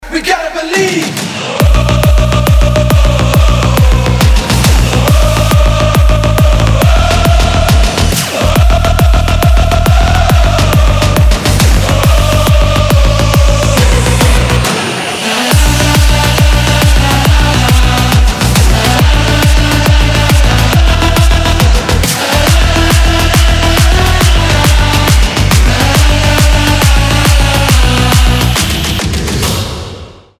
• Качество: 320, Stereo
громкие
psy-trance
транс
Стоящий trance для футбольного болельщика